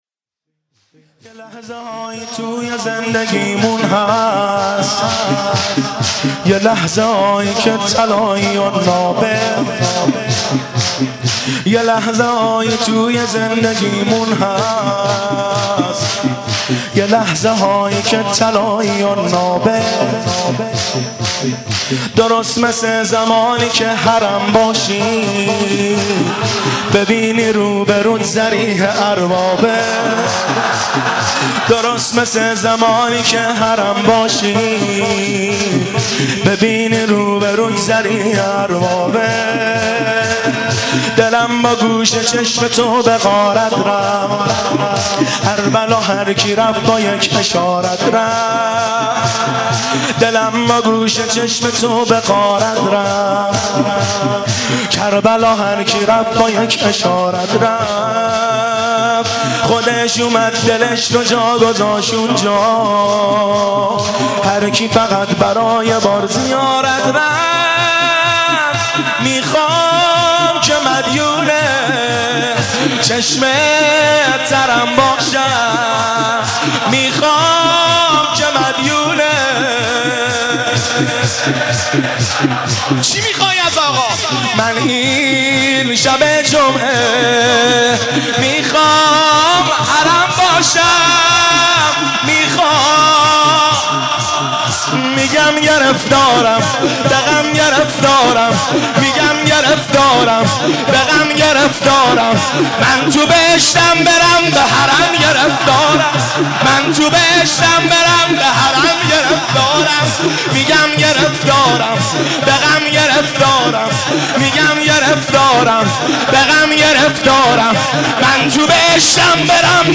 این مداحی به صورت مناجات می باشد.